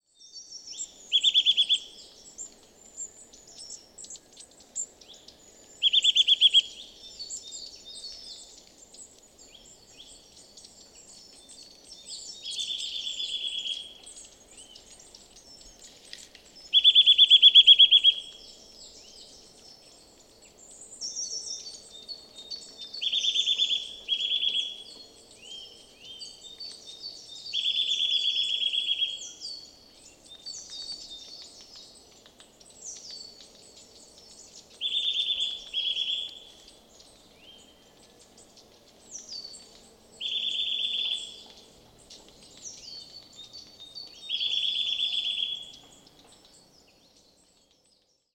Boomklever geluid
• De boomklever, bekend als Sitta europaea, maakt een uniek geluid dat je kan herkennen aan het luide ‘wiet, wiet, wiet’.
• Hun zang bevat variaties zoals snelle trillers en langzame, melodieuze tonen, vooral tijdens de voortplantingsperiode om partners aan te trekken.
Je hoort vaak een herhaald, luid ‘wiet, wiet, wiet’.
Snel triller en langzame series van fraaie tonen zijn voorbeelden van de verschillende geluiden die het maakt.